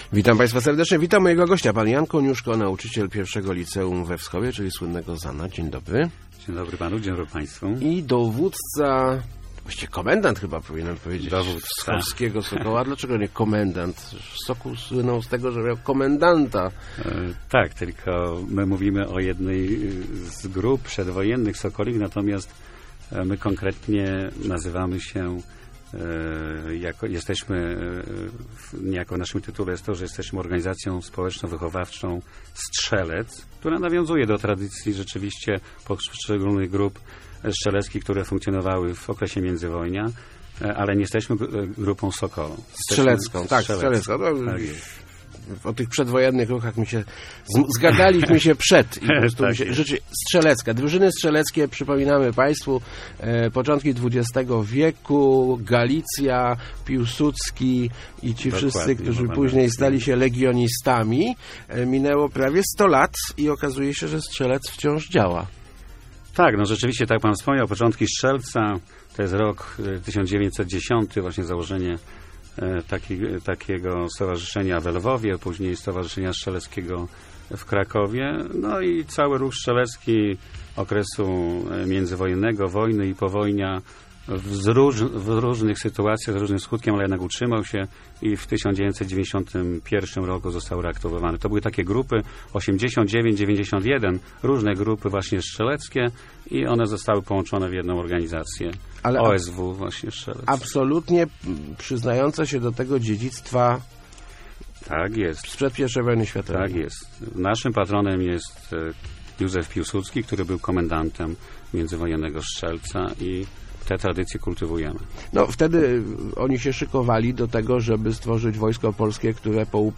Leszno, Portal Regionu, regionalne, lokalne, radio, elka, Kościan, Gostyń, Góra, Rawicz, Wschowa, żużel, speedway, leszczyński, radio, gazeta, dodatek